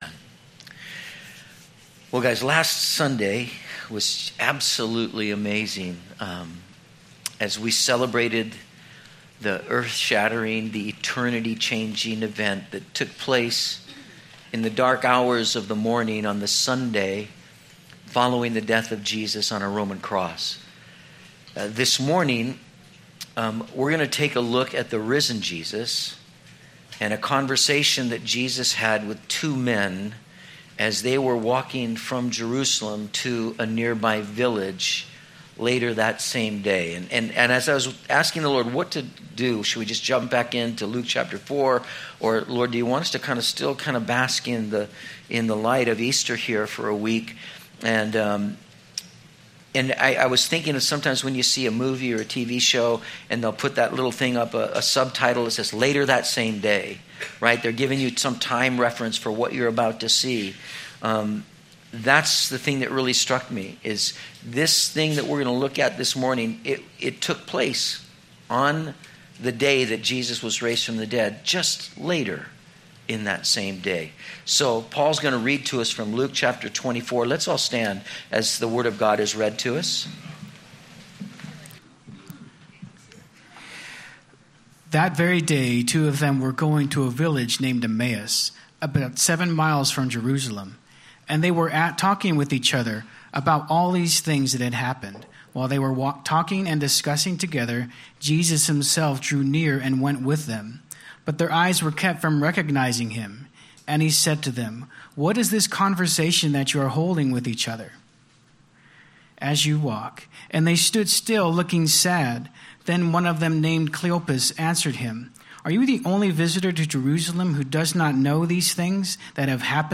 04/03/16 Later That Same Day - Metro Calvary Sermons